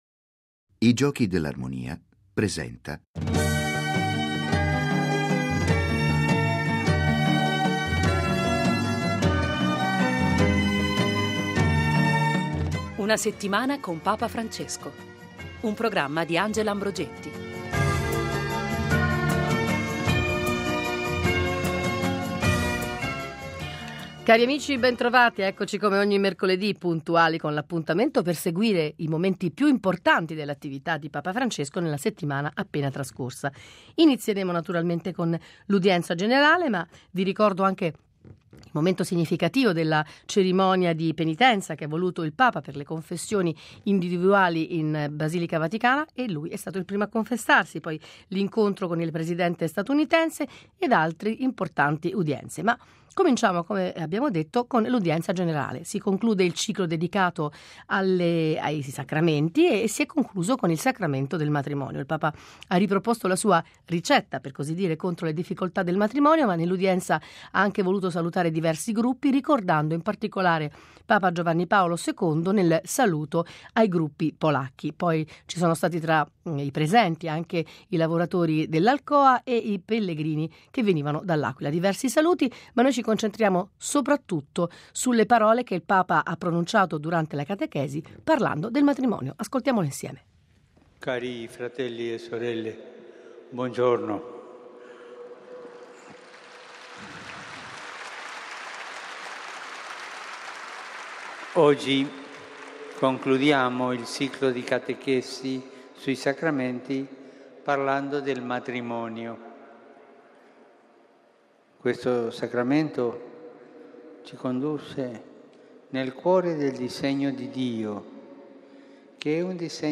mercoledì 02 aprile (ore 21.00) Venticinque minuti ogni settimana per riascoltare i discorsi, rivivere gli incontri, raccontare le visite di Papa Francesco. Qualche ospite e la lettura dei giornali, ma soprattutto la voce del Papa a partire dall’appuntamento del mercoledì per l’Udienza Generale.